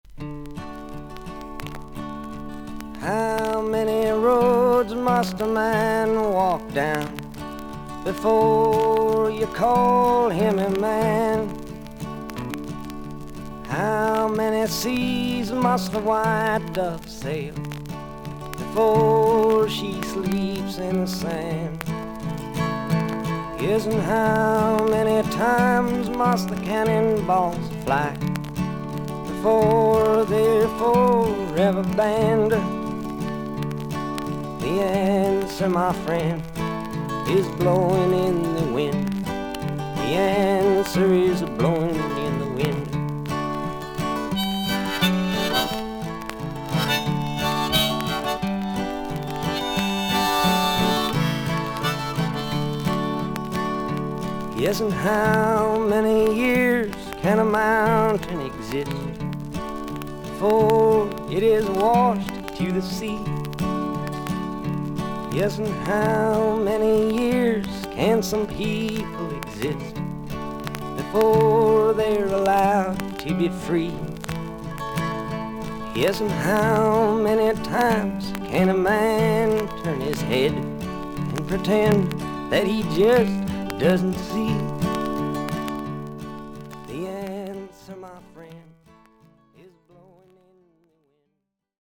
全体的に少々軽いパチノイズ、少々サーフィス・ノイズあり。音自体はクリアです。